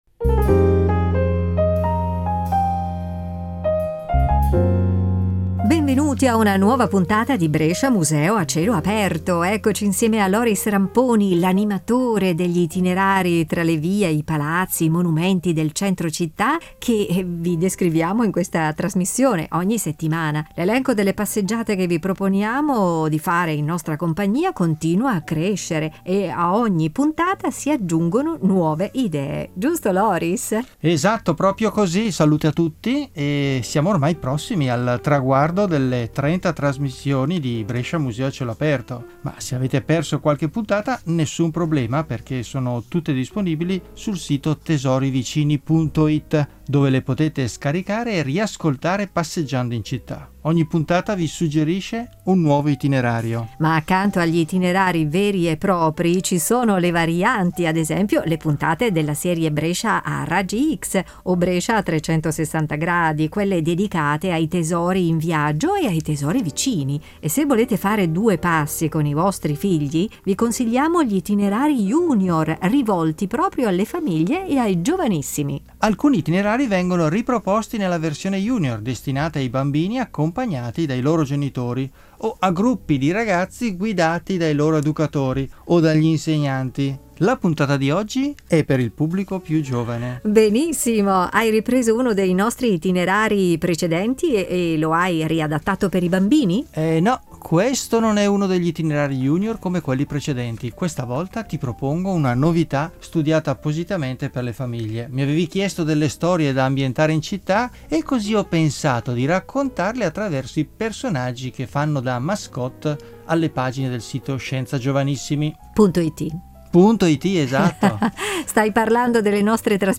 audio-guida junior e itinerari junior per passeggiare tra le vie di Brescia